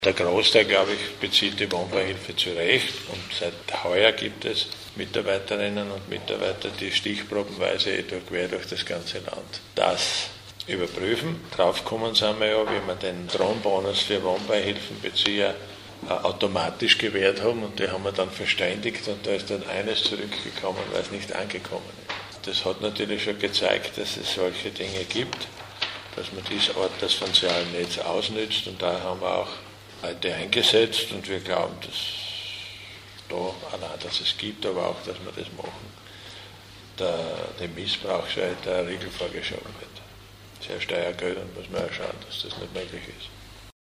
Nachfolgend finden Sie O-Töne aus der heutigen Pressekonferenz:
Antwort LH-Stv. Siegfried Schrittwieser